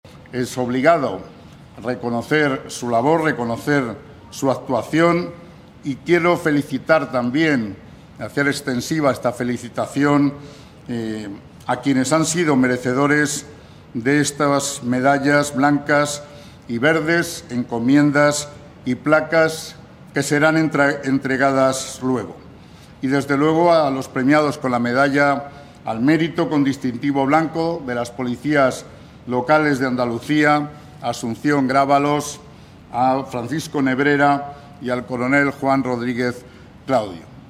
TOMAS-BURGOS-VICECONSEJERO-PRESIDENCIA-CONGRESO-TECNICO-POLICIAL.mp3